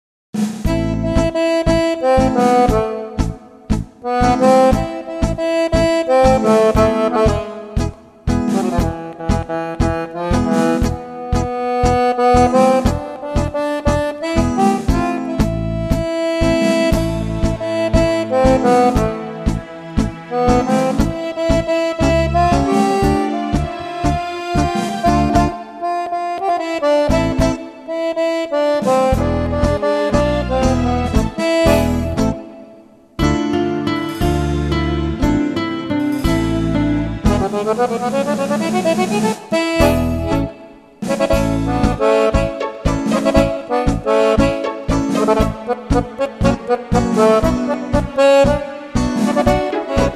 Fisa